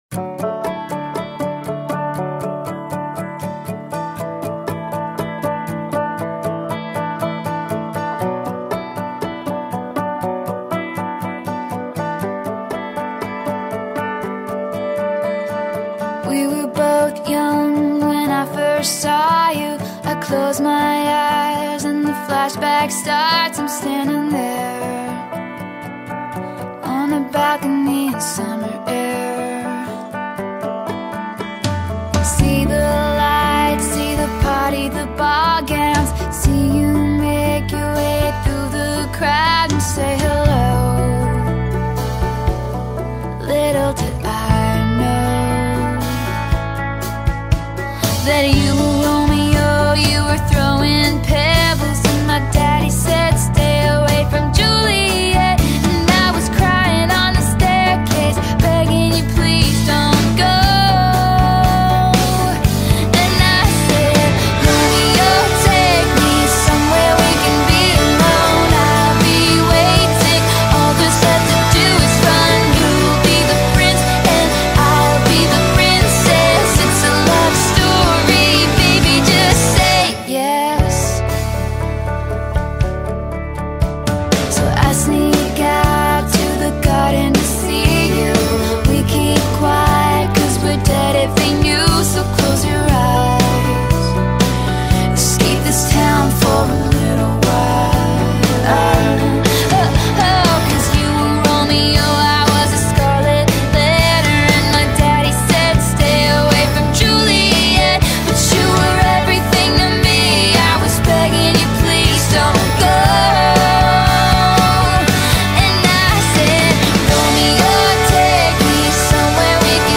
ژانر: پاپ / راک